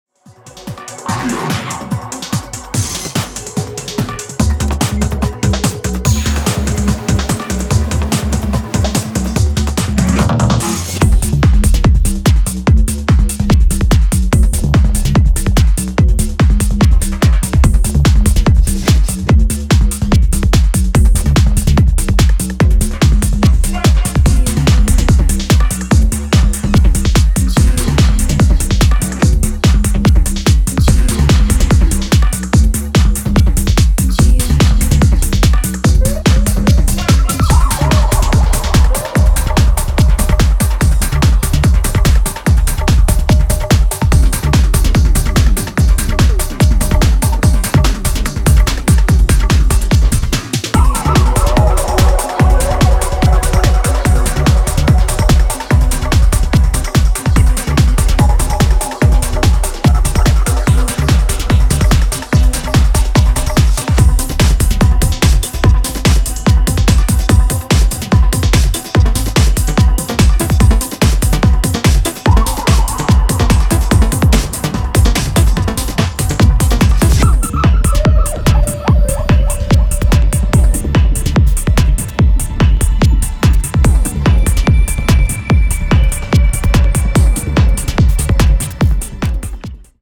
更にギアを上げた高速BPMで幻想的に駆け抜ける
弾性高いキックの鳴りが爽快、フロアのテンションをしっかりブチ上げてくれます！